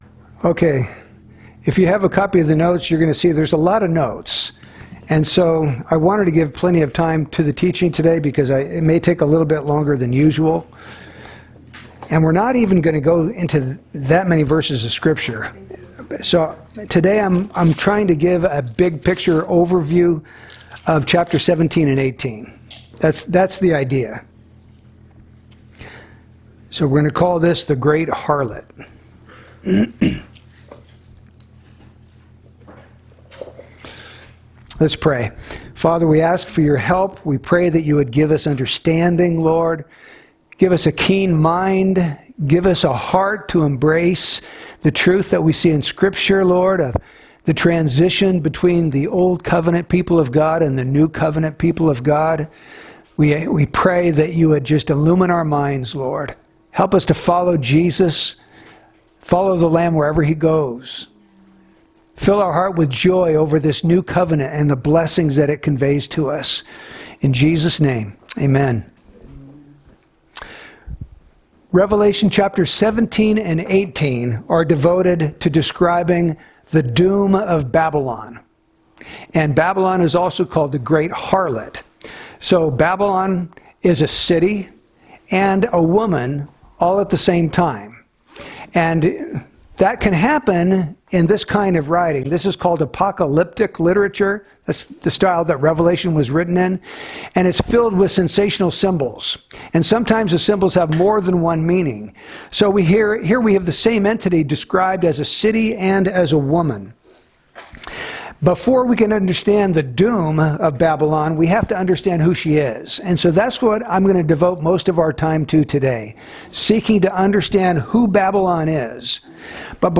Who is Babylon, the Great Harlot of Revelation 17 & 18? That is precisely the question we seek to answer in this teaching.